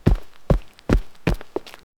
Single human footstep sound effect for a video game. One step only, not a loop, not a sequence. No music, no rhythm, no ambience. Surface: dry sand. Footwear: boots. Movement: normal walk. Dry, close microphone, short tail. Clean transient, unprocessed.
single-human-footstep-sou-yqbkcss4.wav